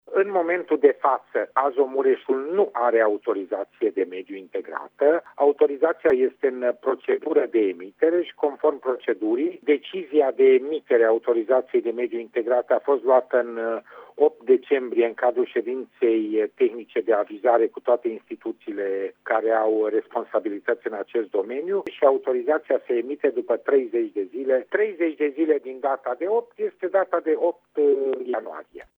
Şeful Agenţiei de Protecţia Mediului Mureş, Dănuţ Ştefănescu, a declarat pentru RTM că în 8 decembrie s-a luat decizia emiterii autorizaţiei integrate de mediu pentru Azomureş, în termen de 30 de zile: